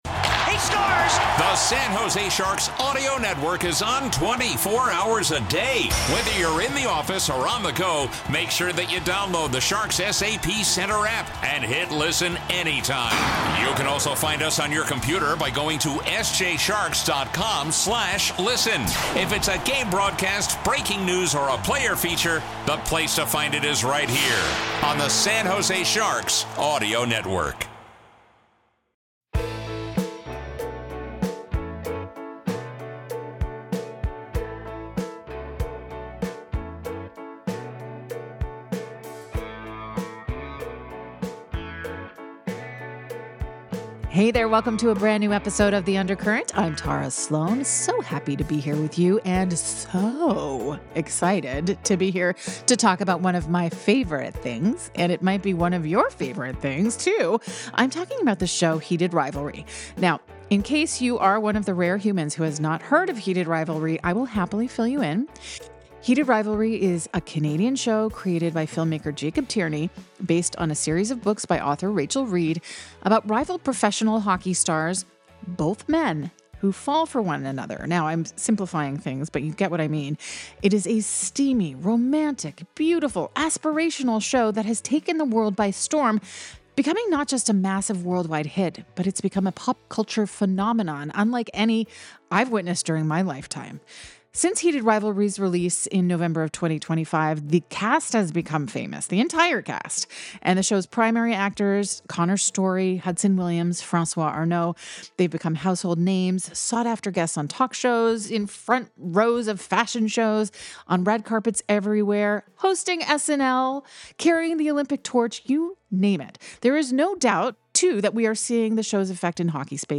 Broadcaster, musician, and talk show host Tara Slone talks with unique people around hockey and the San Jose Sharks community.
Plus, the panel discusses the recent policy change at USA Hockey that bans transgender players.